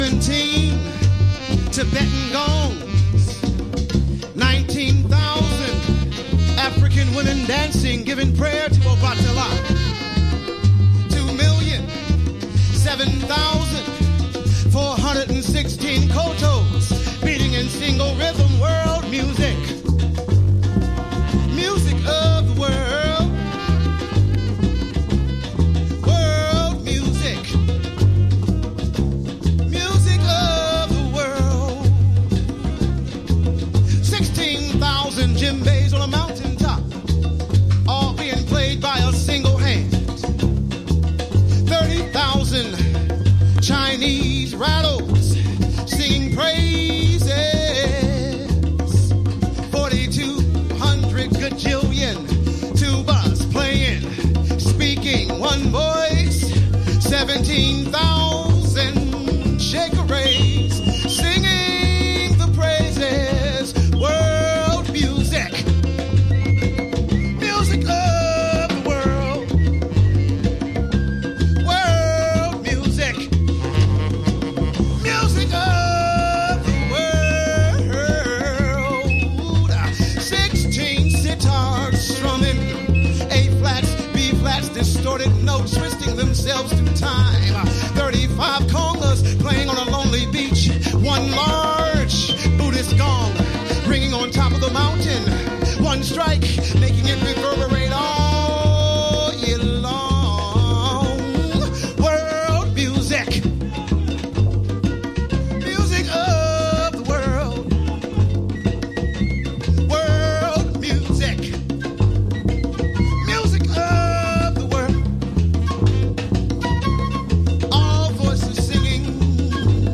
House / Techno
NY DEEP HOUSE / 70's BLACK JAZZとの親和性をグイグイ感じることができます。